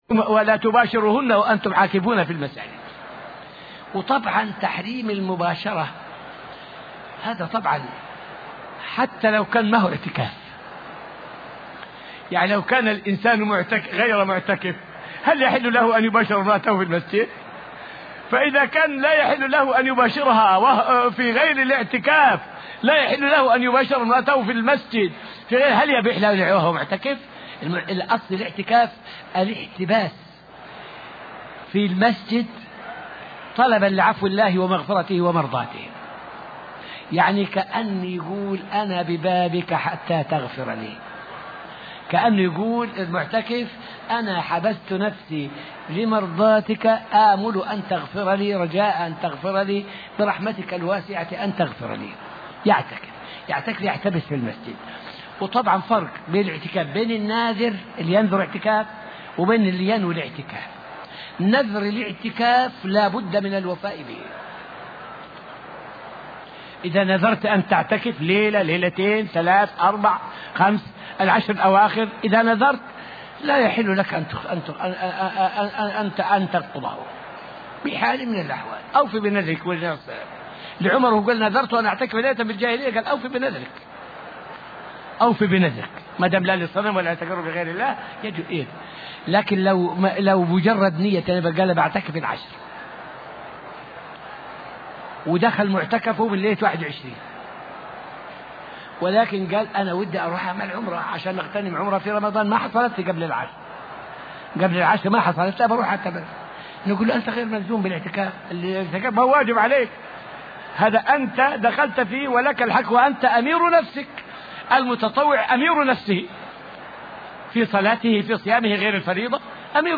فائدة من الدرس الثامن من دروس تفسير سورة القمر والتي ألقيت في المسجد النبوي الشريف حول الاعتكاف في المسجد وهل هو خير من السعي في طلب الرزق؟